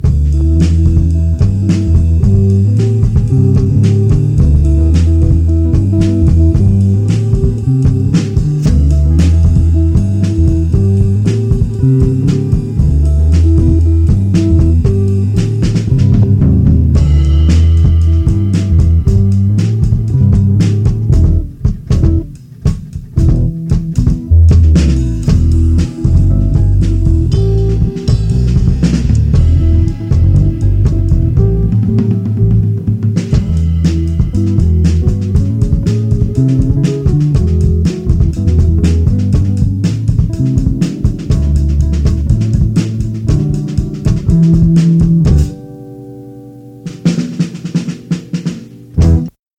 Genre: Lo-fi.